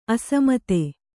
♪ asamate